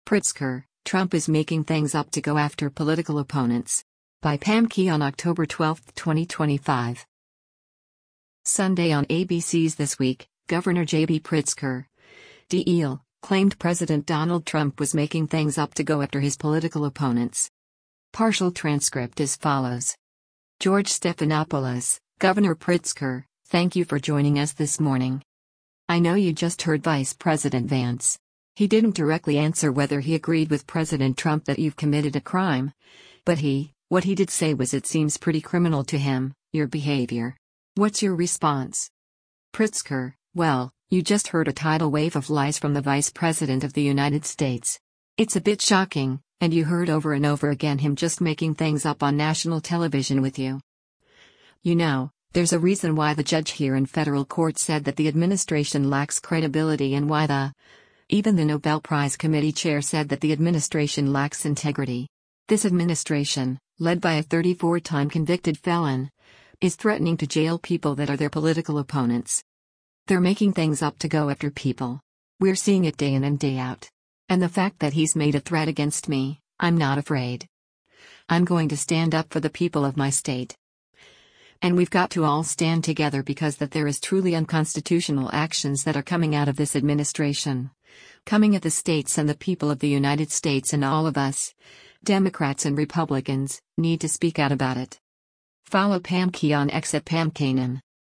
Sunday on ABC’s ‘This Week,” Gov. JB Pritzker (D-IL) claimed President Donald Trump was “making things up to go after” his political opponents.